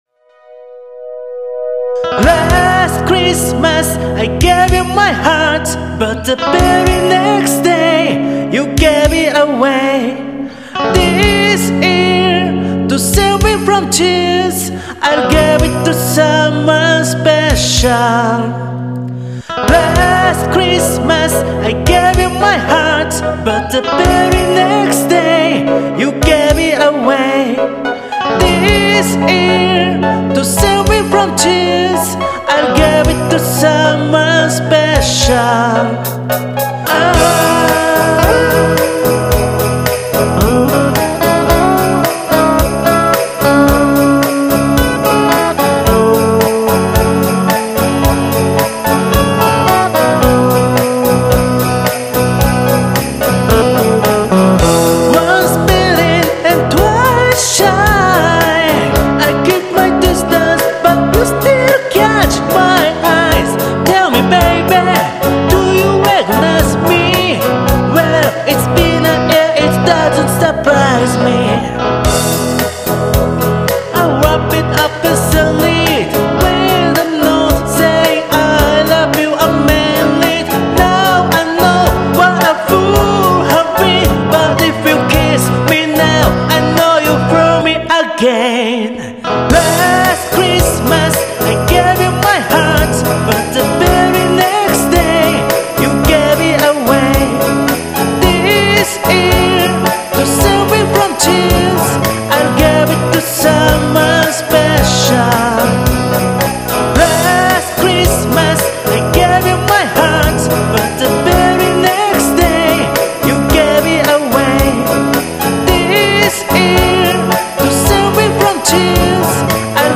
え～っと。。久々に歌モノでも。。ｗ
本家の原曲より1音高いのを無理矢理歌ってしました。。
えぇ。。無理しすぎてハズシまくってます。。il||li ＿㊙￣|○ il||li